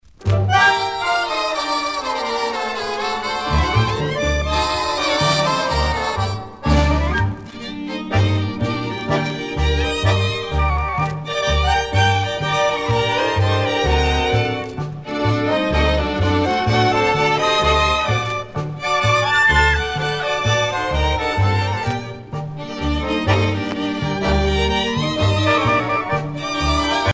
danse : tango
Pièce musicale éditée